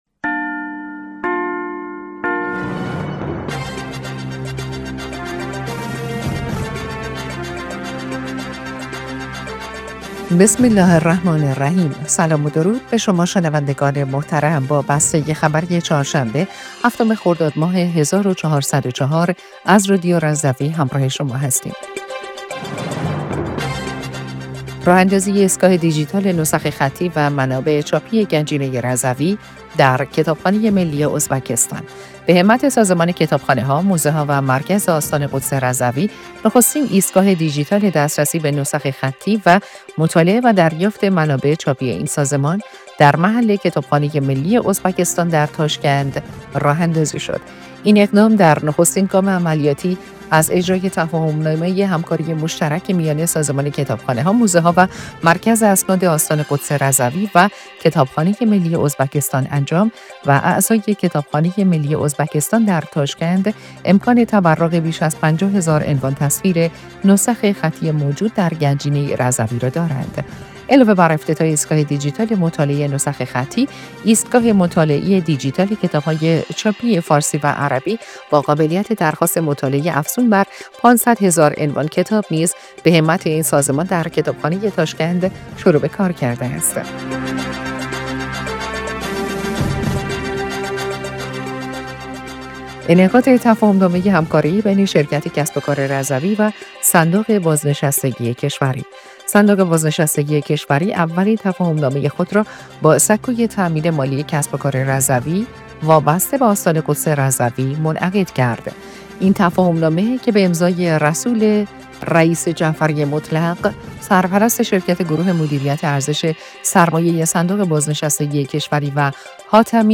بسته خبری ۷ خرداد رادیو رضوی/